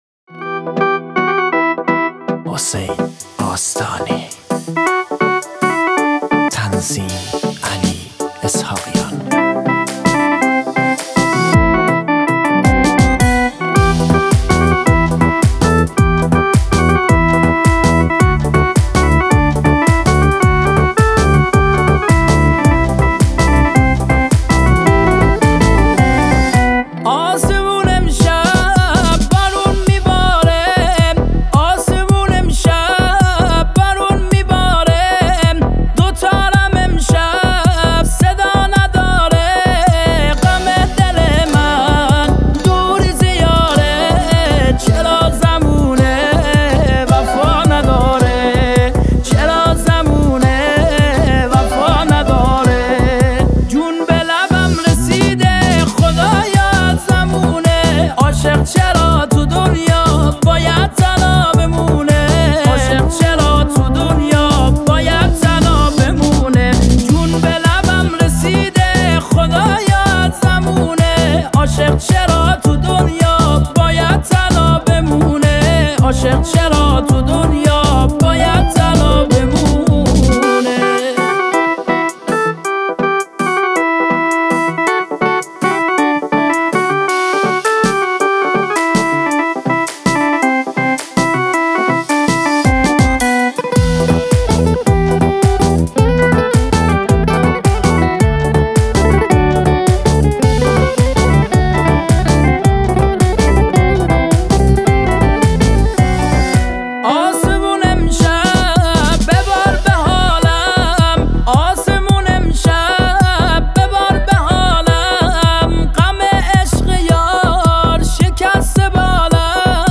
محلی سبزواری